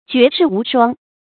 絕世無雙 注音： ㄐㄩㄝˊ ㄕㄧˋ ㄨˊ ㄕㄨㄤ 讀音讀法： 意思解釋： 絕世：冠絕當代；無雙：獨一無二。